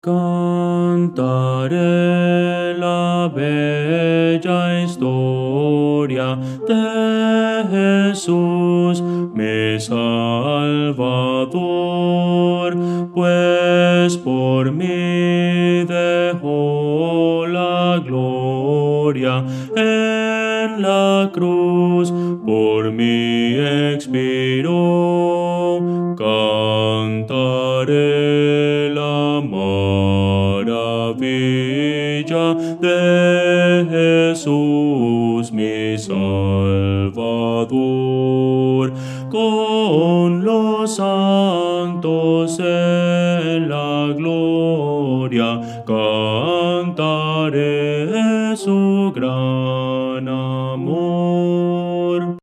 Voces para coro
Bajo – Descargar